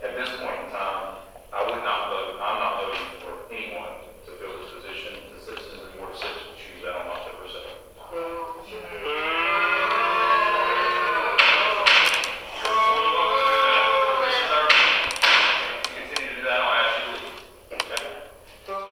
The audience booed.
Odham hit the gavel.
They booed again.
Boo.mp3